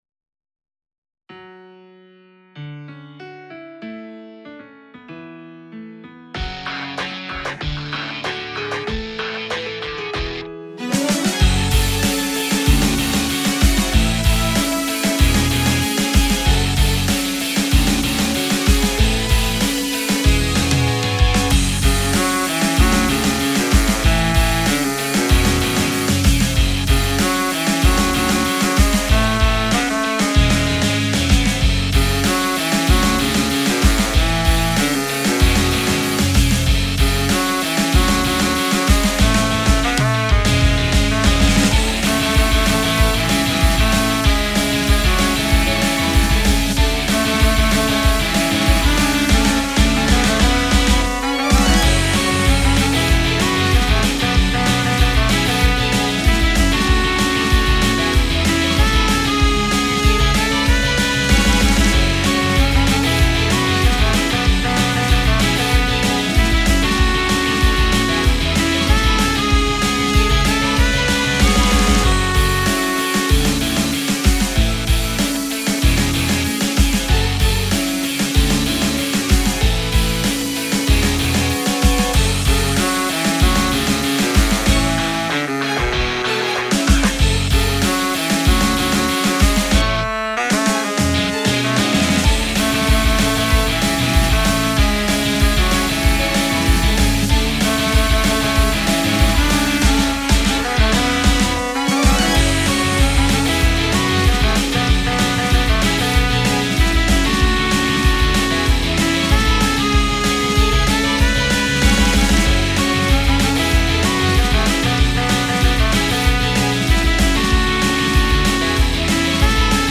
ロック
インスト版（カラオケ）　メロディあり